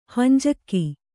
♪ hanjakki